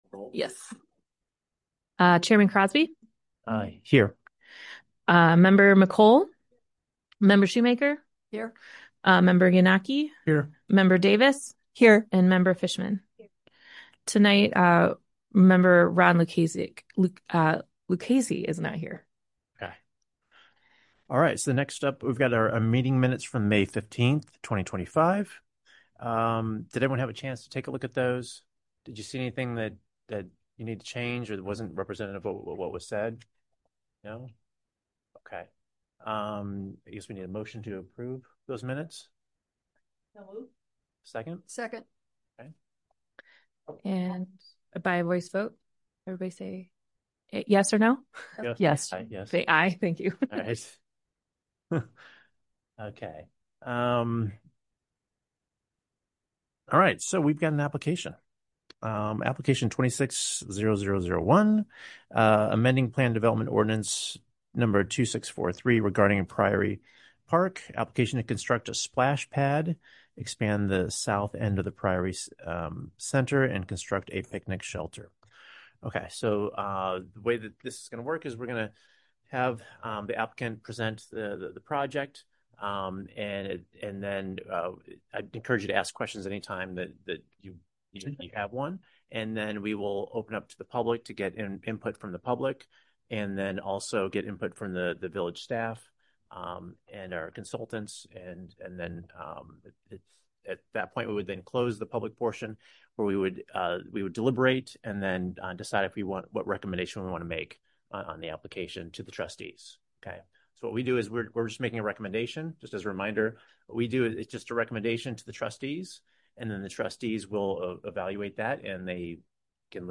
Development Review Board Meeting
Village Hall - 400 Park Avenue - River Forest - IL - 1st Floor - Community Room